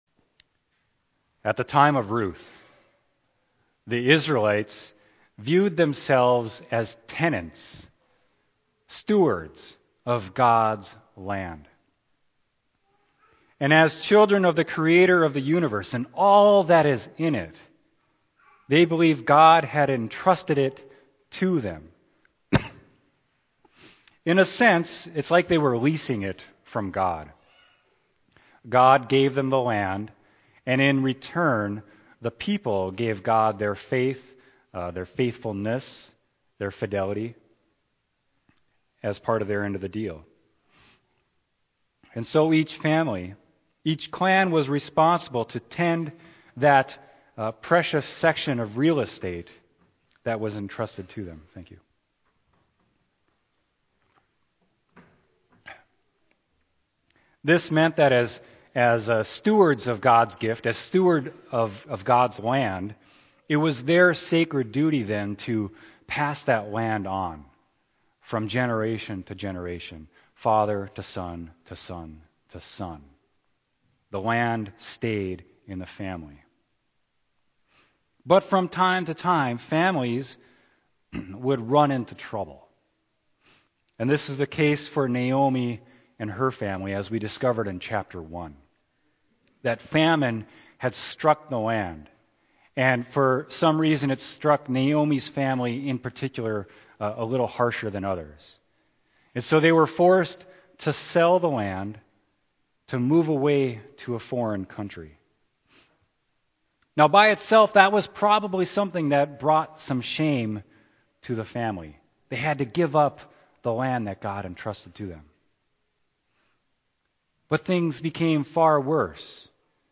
sermon | Peace Lutheran Church | Page 64